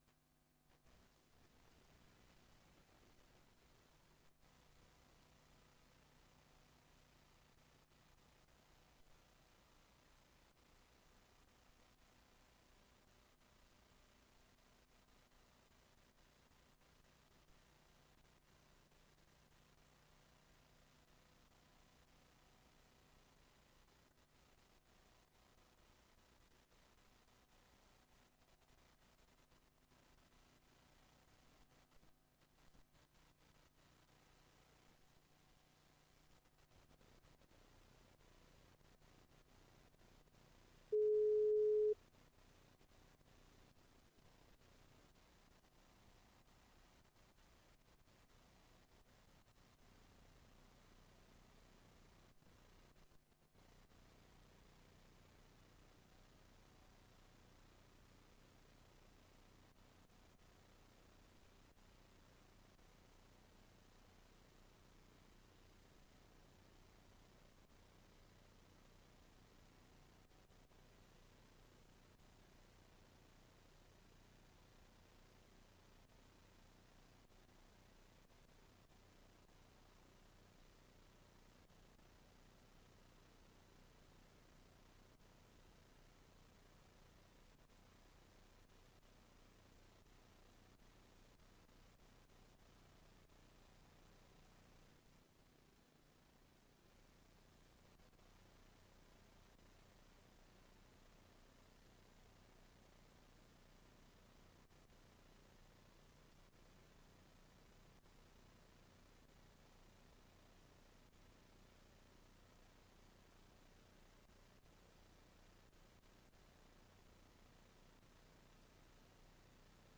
Raadsbijeenkomst 17 september 2024 19:30:00, Gemeente Tynaarlo
Locatie: Raadszaal